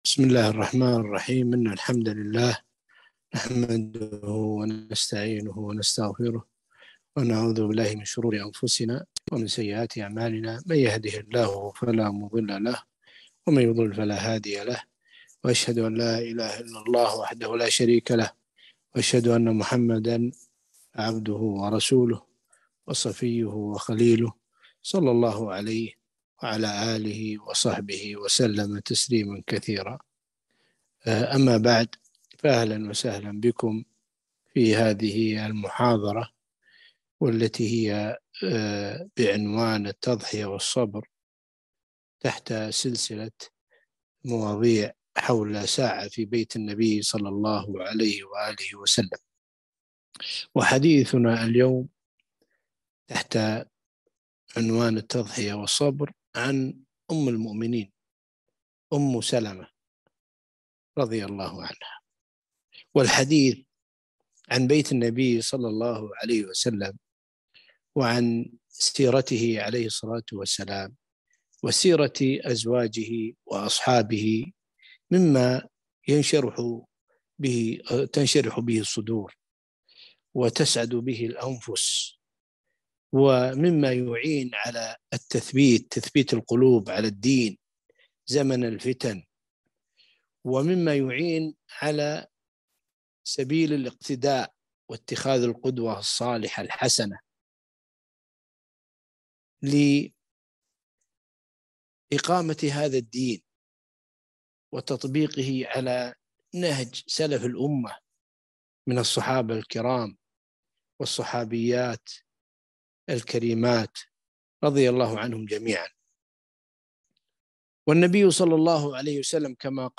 محاضرة - التضحية والصبر